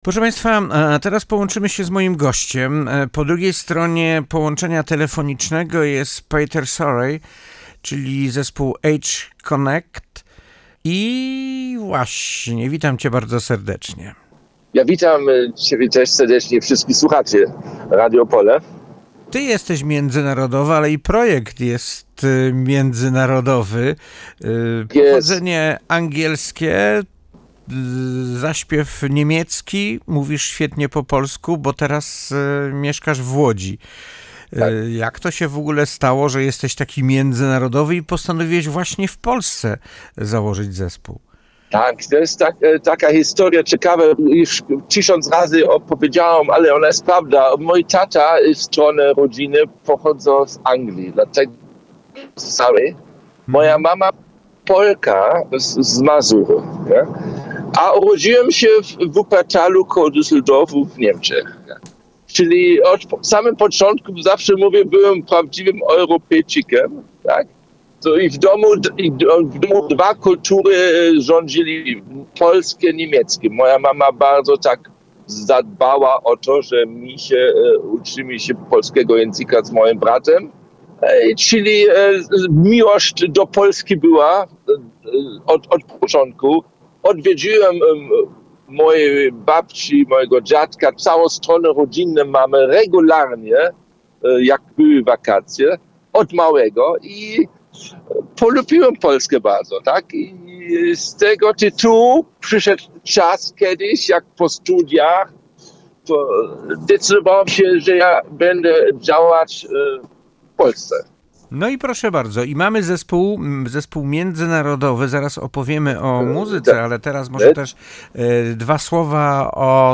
We’re thrilled to share that we were recently guests on Radio Opole, one of Poland’s most respected regional broadcasters!
During the interview, we had the chance to talk about the story behind our project, the creative process behind our music, and what drives us to keep delivering energetic, uplifting pop. The conversation was warm, insightful, and full of good vibes — just like our songs!